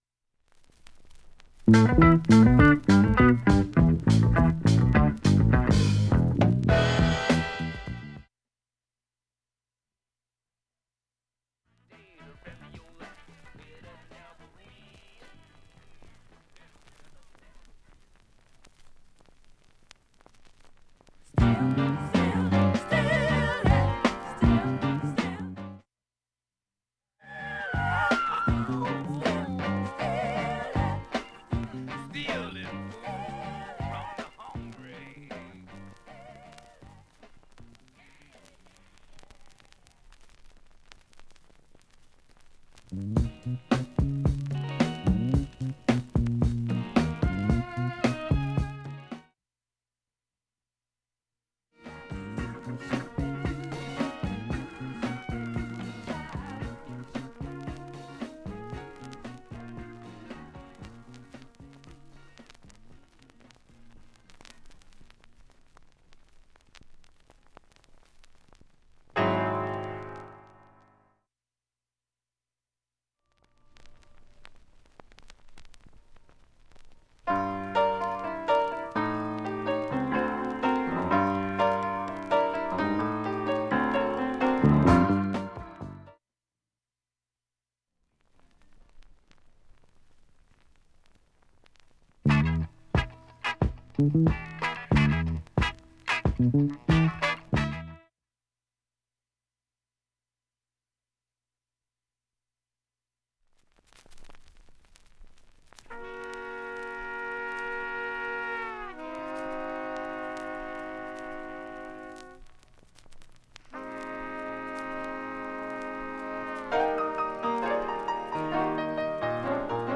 曲間にチリ音が出ています、
演奏中は問題の無いレベルです。
音質良好全曲試聴済み。